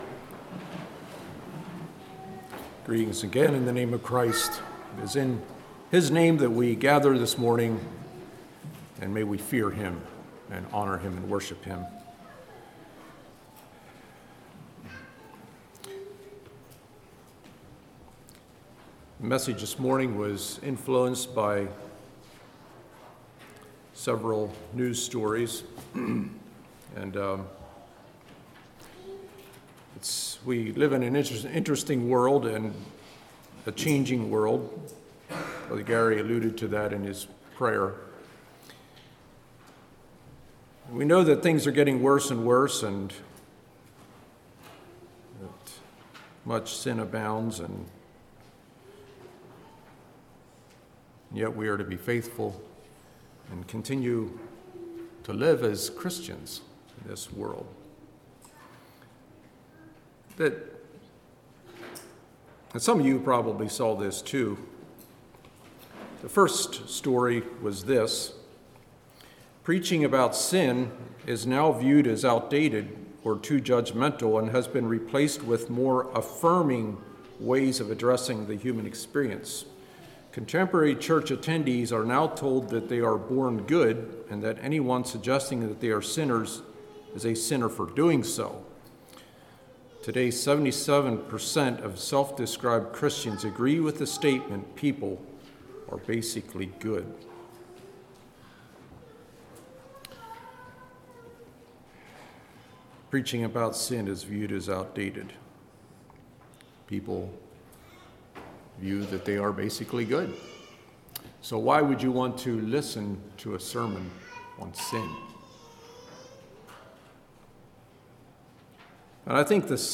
Congregation: Kirkwood